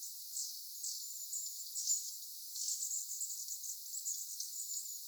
onko nuokin pyrstötiaisen ääniä?
onko_nuokin_pyrstotiaisen_aania.mp3